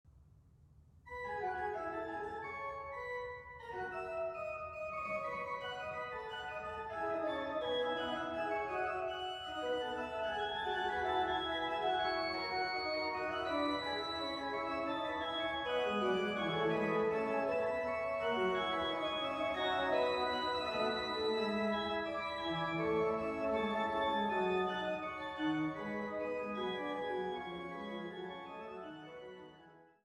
gespielt an der Trost-Orgel der Schlosskirche Altenburg